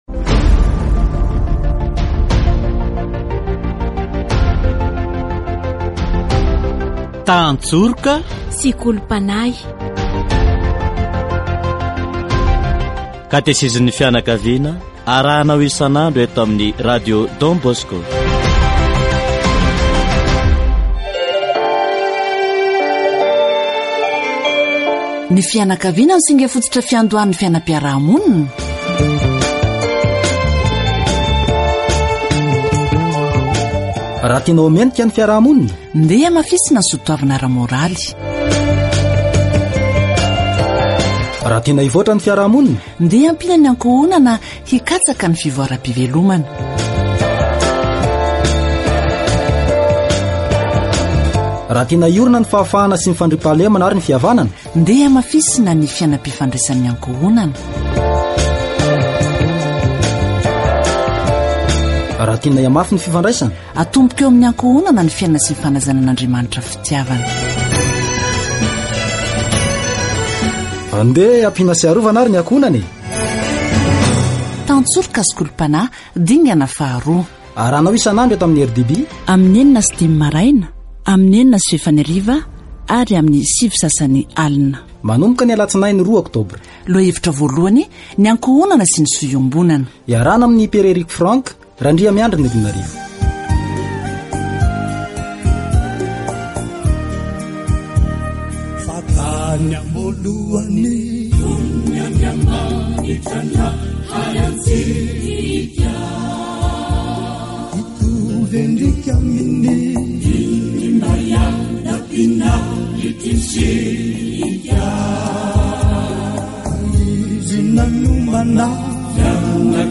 Catégorie : Approfondissement de la foi
La famille est l'élément fondamental de la société ; si vous voulez que la société soit décente, renforçons les valeurs morales ; si vous voulez que la société se développe, aidons la famille à rechercher le développement de ses moyens de subsistance ; si nous voulons que la liberté, la paix et l'union s'établissent, renforçons les relations familiales. Catéchèse sur la famille